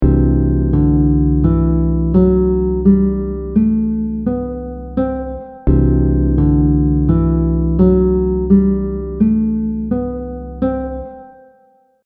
The audio examples will repeat the scale over a chord so you can get a better grasp of the sound of each mode.
• Mood / emotion: bright, hopeful 🙂
• Characteristic note: sharp 4
You can think of the Lydian scale as a Major scale with a sharp 4th degree.
C Lydian scale audio example